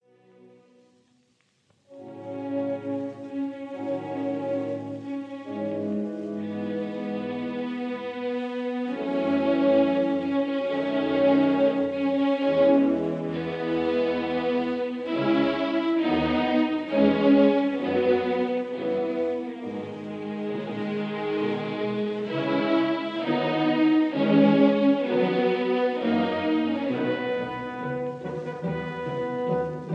This is the fourth marked Andante maestoso— Allegro vivace.